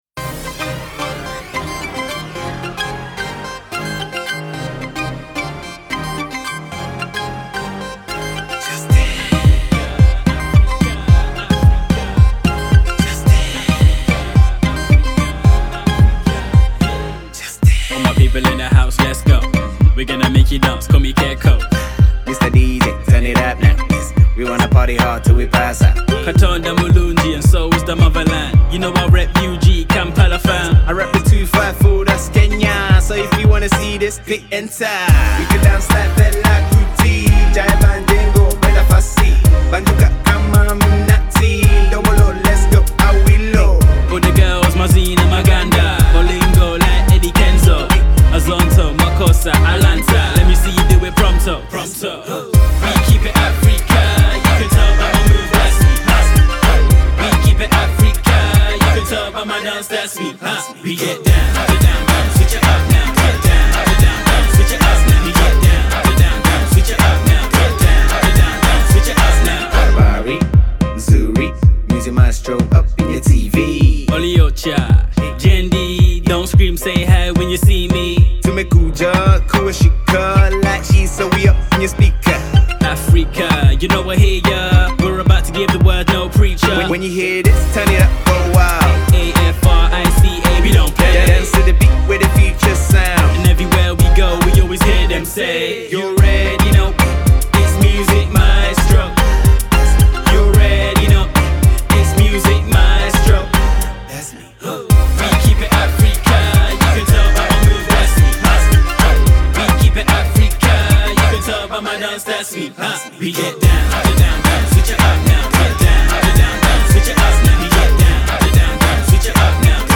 East African Kapuka Beat